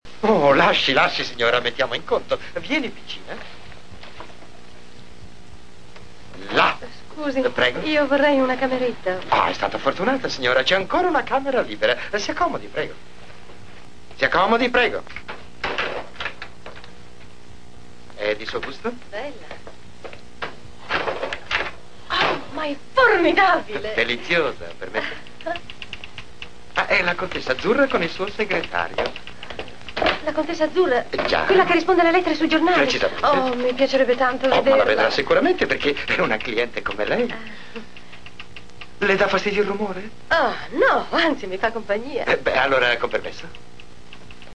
voce di Cesare Fantoni nel film "La spiaggia", in cui doppia Enrico Glori.